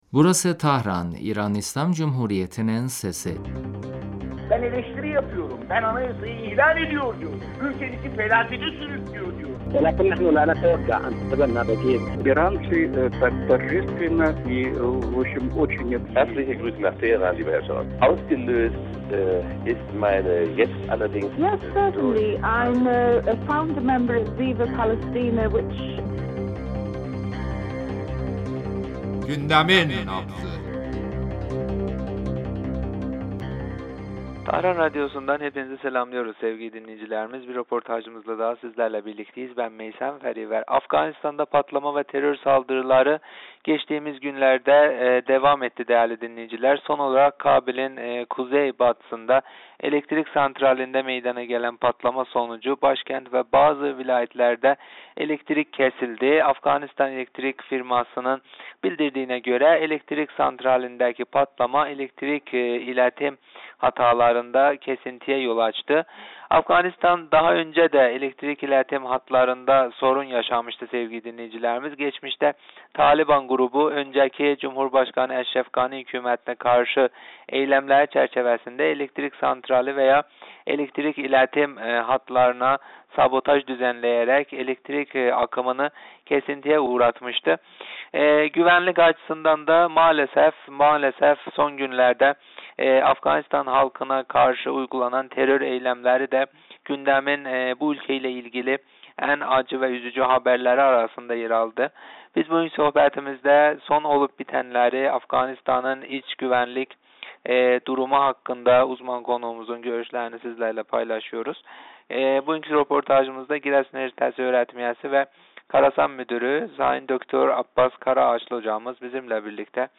radyomuza verdiği demecinde Afganistan'da cereyan eden son gelişmeler etrafında görüşlerini bizimle paylaştı.